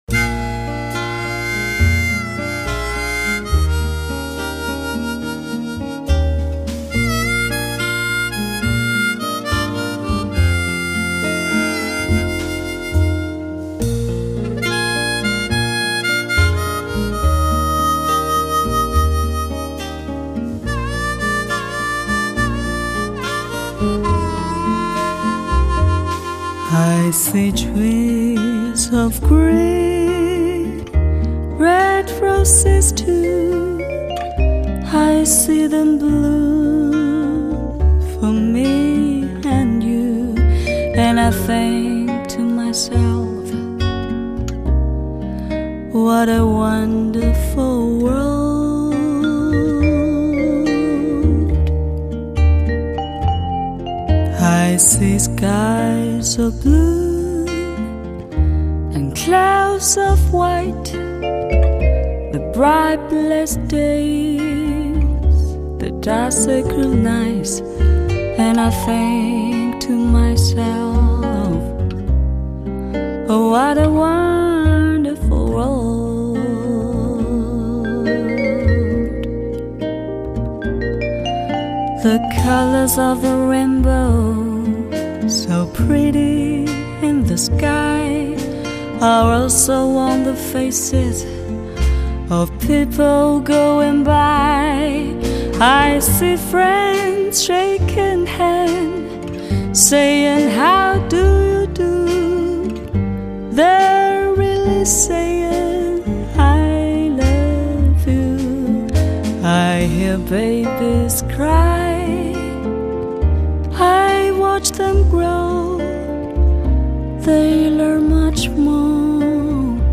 顶级音响器材试音专辑 Hi-Fi sound testing CD with top sound facilities.
HQCD的成品达到了接近原始音乐母带的完美音质。